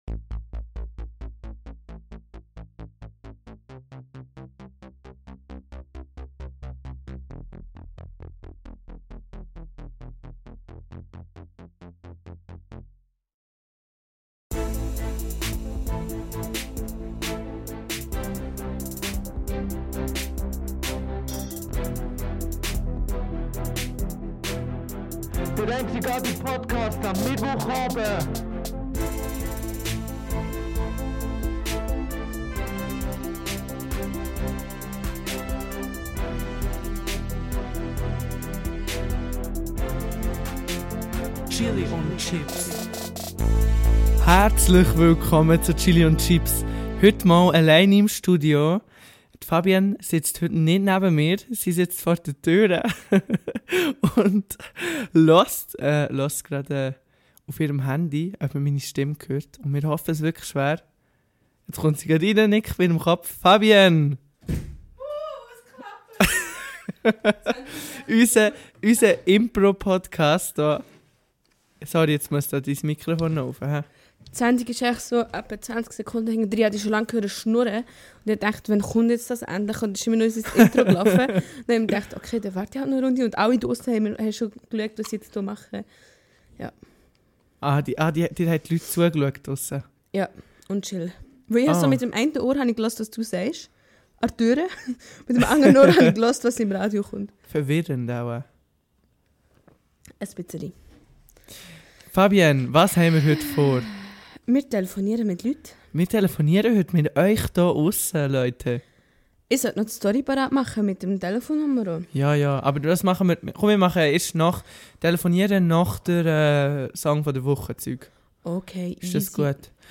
Der Live-Podcast